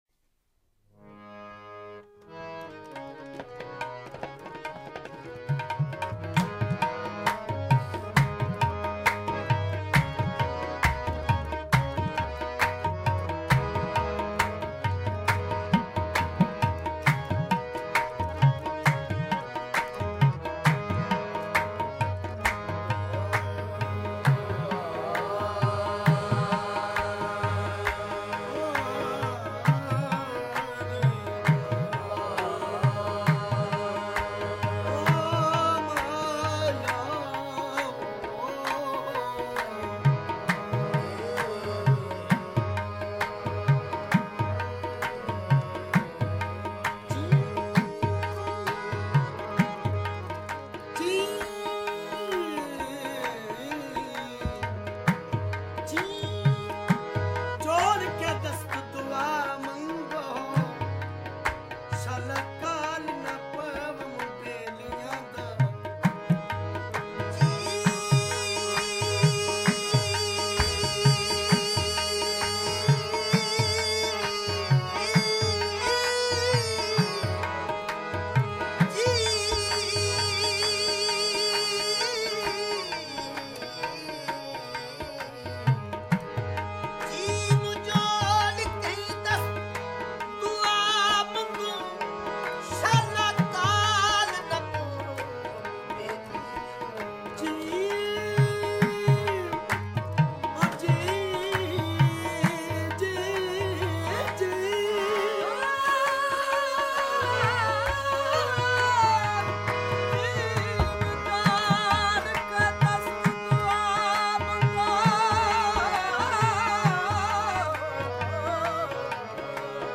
Qawwali Download (Right click + save target as…